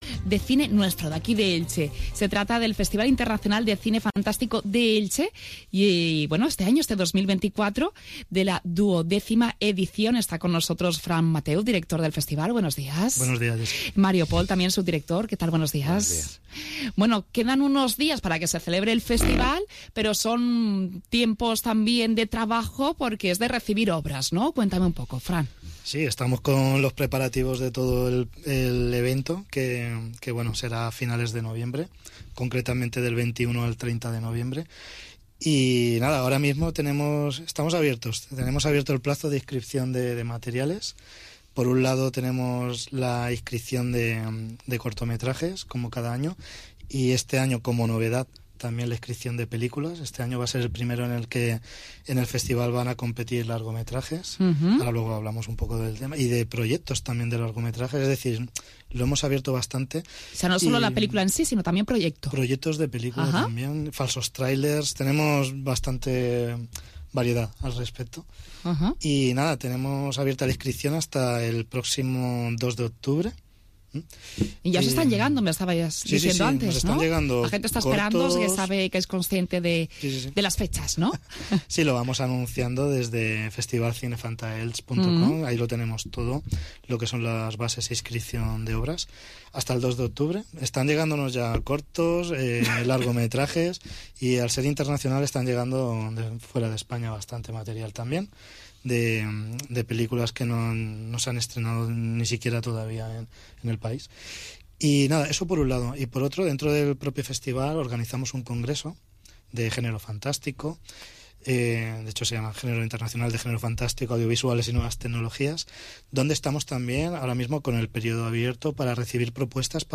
ENTREVISTA EN RADIO ELCHE CADENA SER